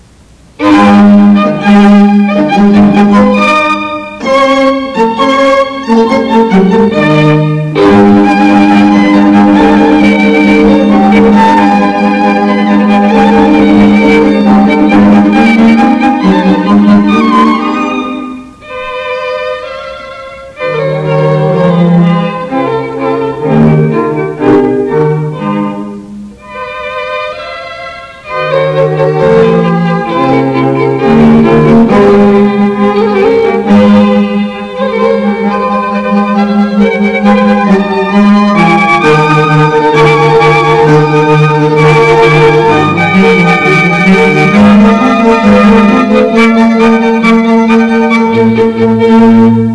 Serenade String Quartet Music Examples
Classical Music Samples